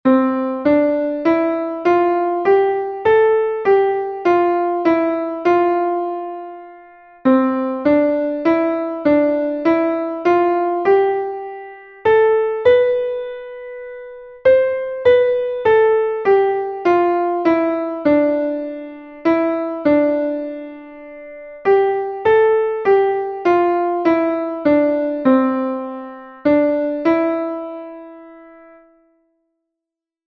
Melodic recognition
Identify incorrect notes or group of notes in this melodic dictation
dict_melodico_6_8_notas_erroneas.mp3